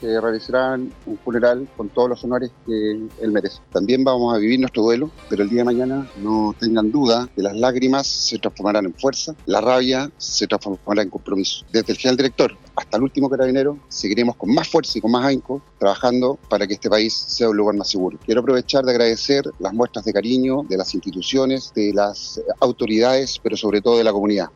El general de Zona de Carabineros en Los Lagos, Jorge Valdivia, aseguró que despedirán con los máximos honores al uniformado.